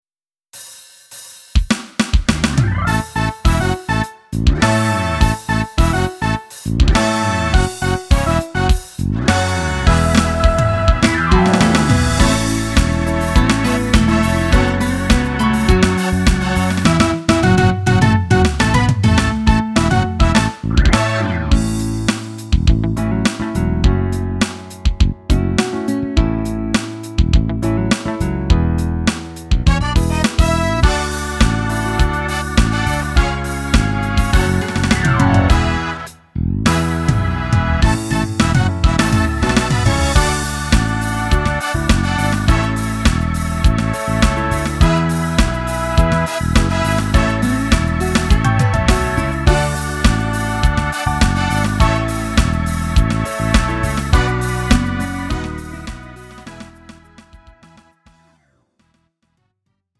음정 원키 2:53
장르 가요 구분 Lite MR